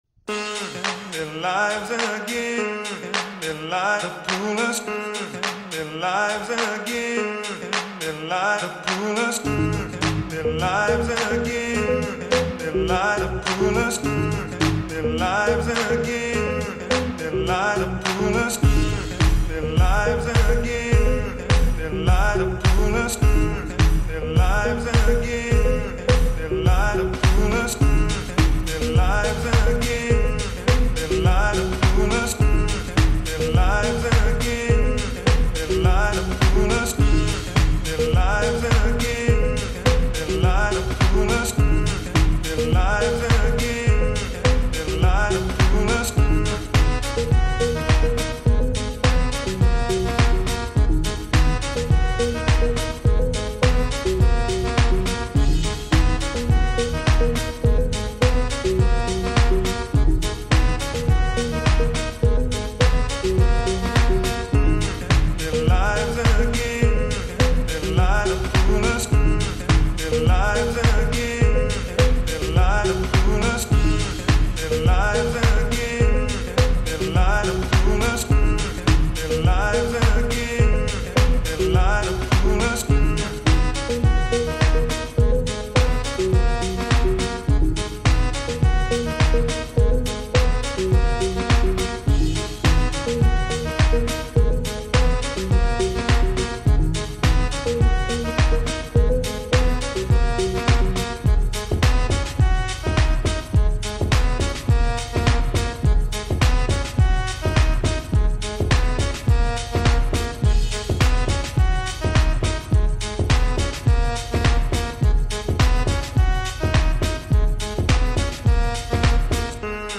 فانک
شاد